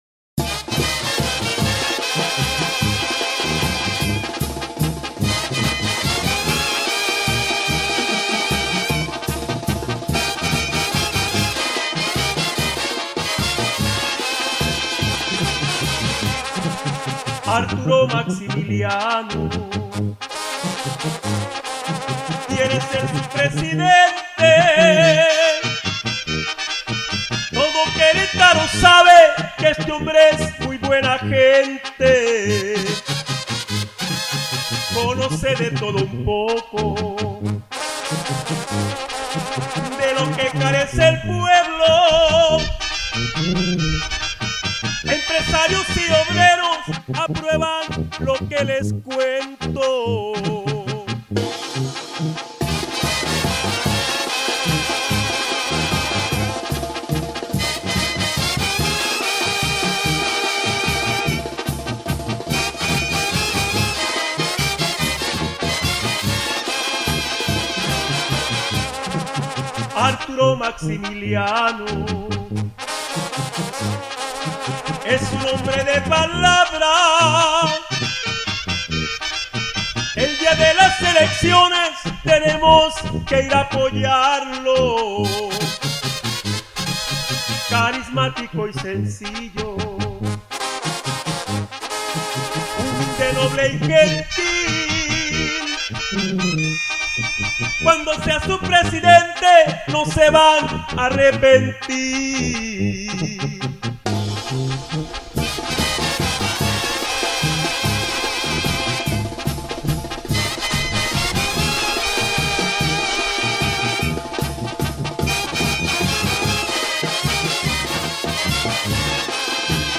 A ritmo de tambora, con acompañamiento de instrumentos de viento, la canción exalta al candidato como “buena gente” y conocedor de las necesidades de la gente.